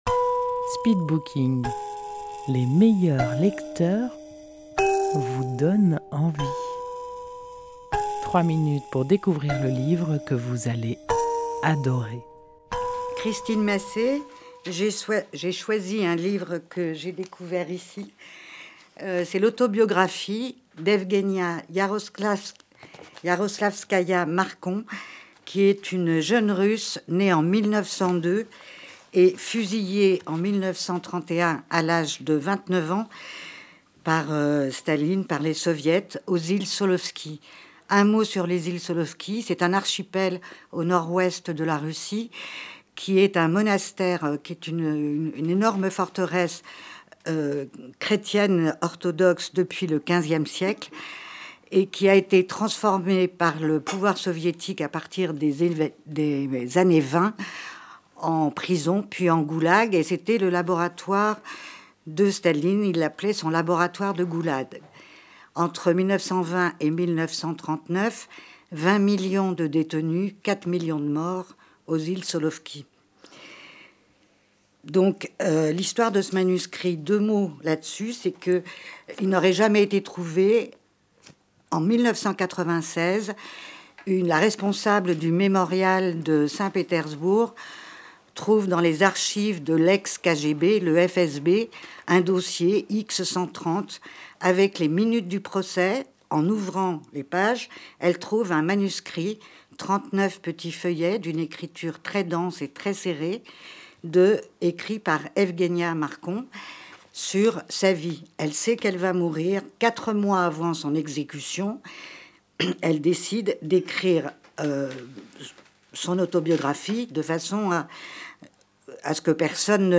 Speed booking Rendez-vous au speed booking : les meilleurs lecteurs vous font partager leur passion pour un livre en 3 minutes chrono. Enregistré en public au salon de thé Si le cœur vous en dit à Dieulefit.